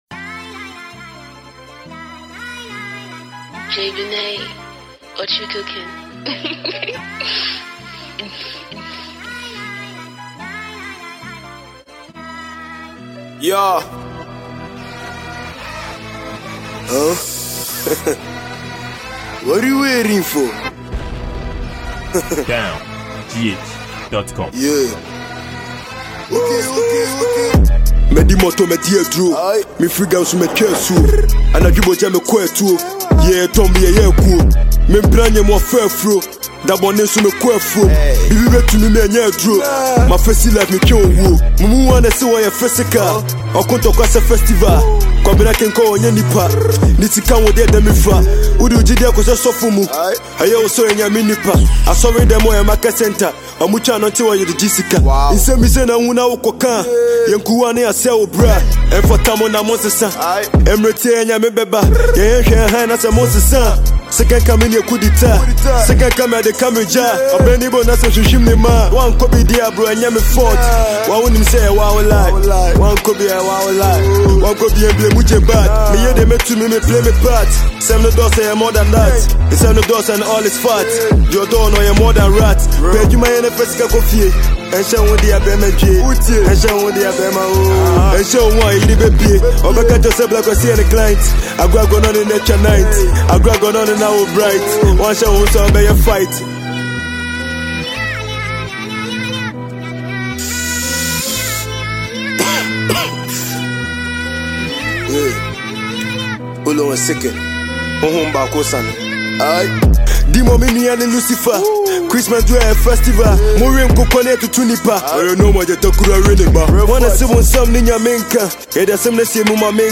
which mixed with drill hiphop and Ghanaia highlife genre.